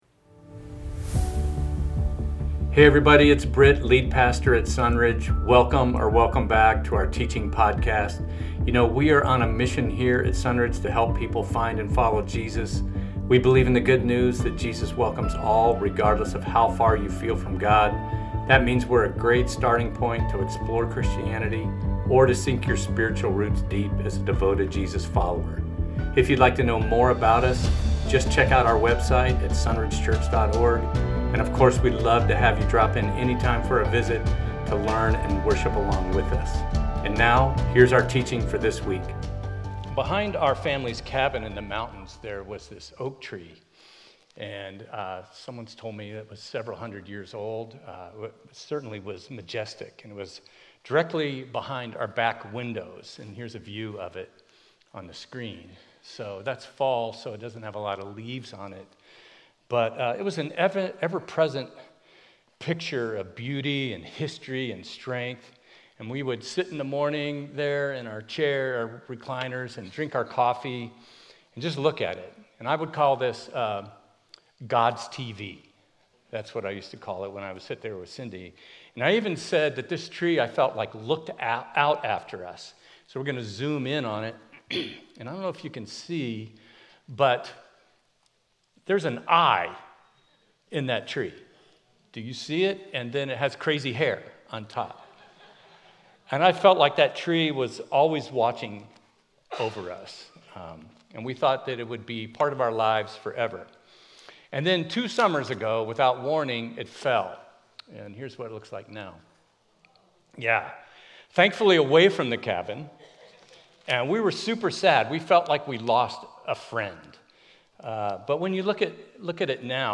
Your Inner Life - Temecula Sermon
Your Inner Life - Sermons at Sunridge Church in Temecula.